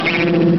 Download I Dream of Jeannie sound effect for free.